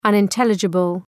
Shkrimi fonetik{,ʌnın’telıdʒəbəl}